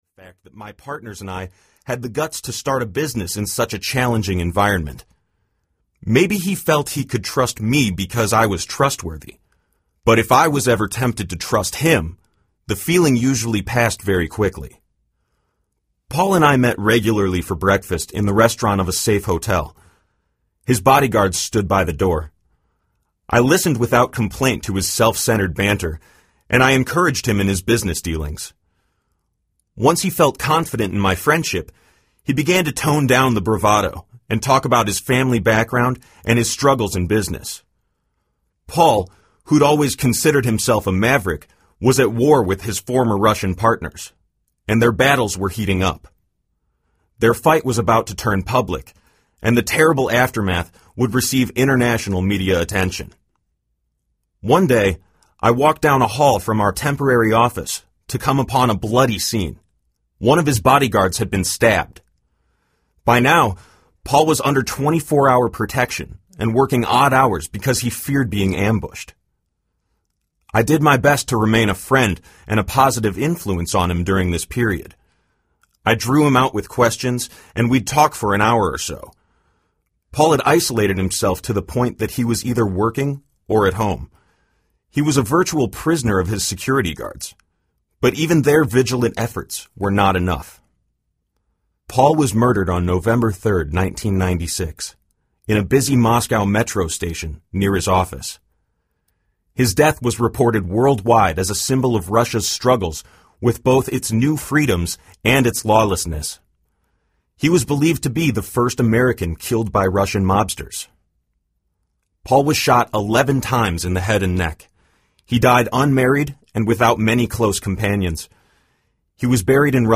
Leadership is Dead Audiobook